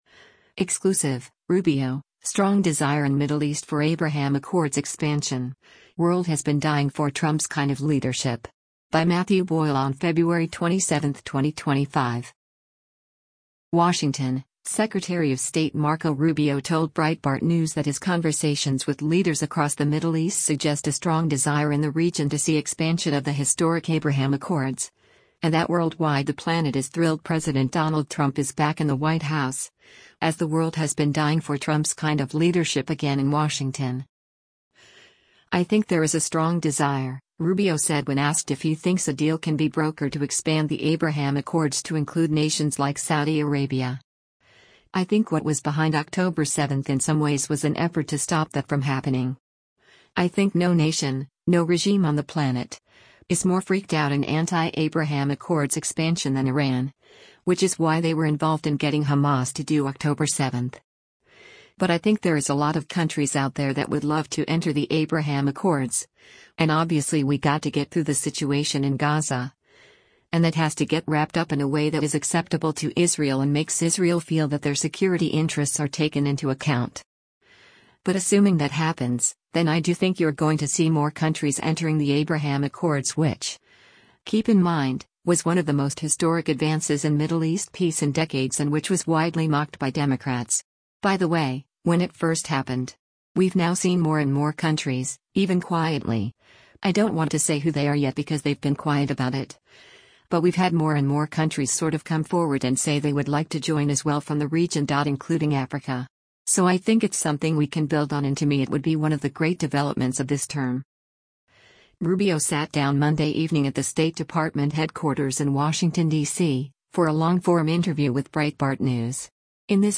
Rubio sat down Monday evening at the State Department headquarters in Washington, DC, for a long-form interview with Breitbart News.